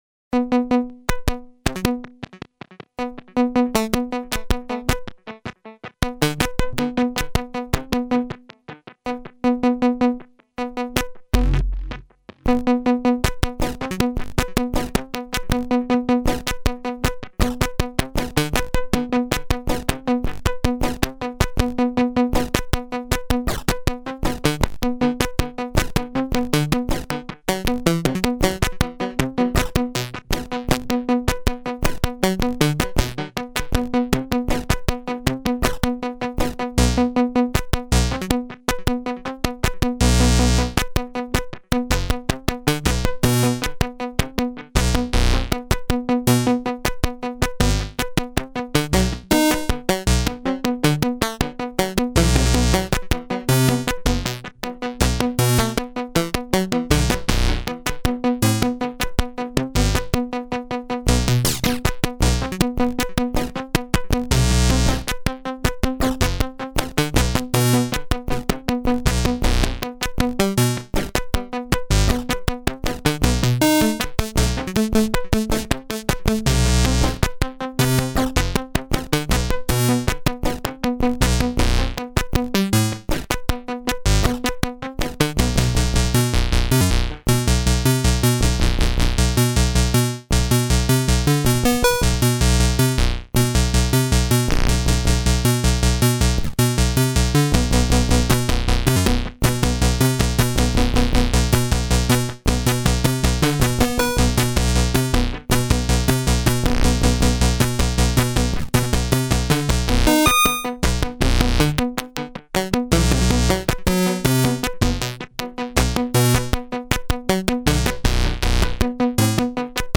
It's very clippy.
Voice 4: Microbrute.